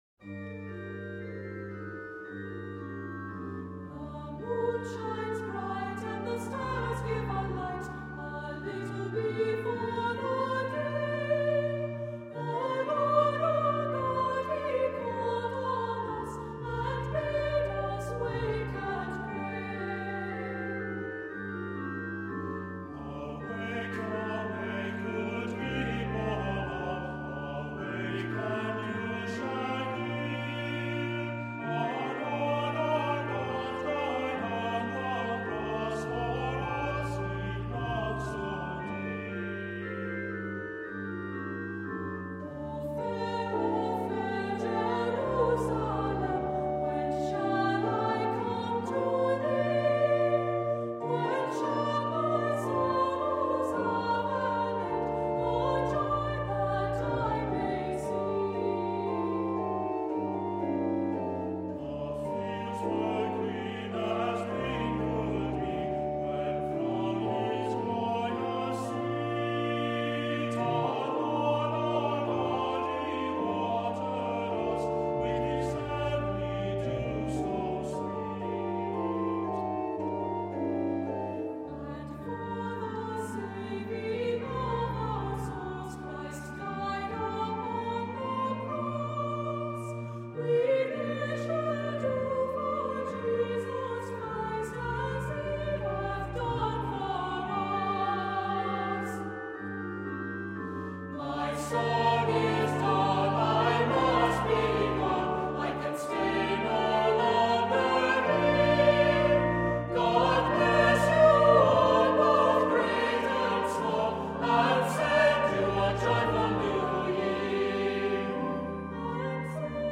Voicing: Two-part mixed